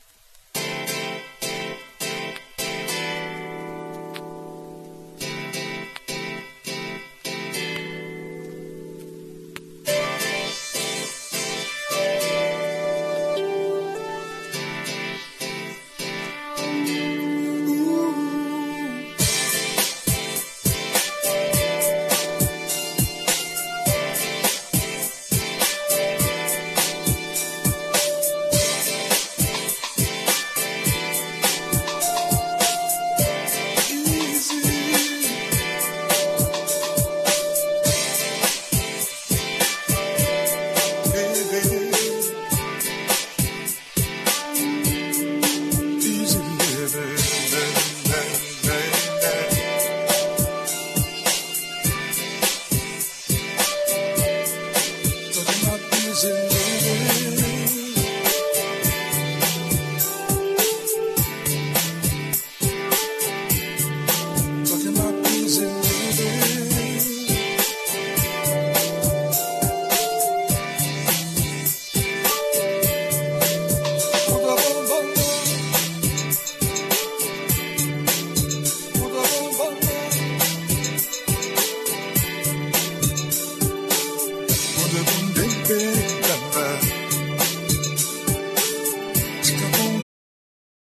CROSSOVER / LOUNGE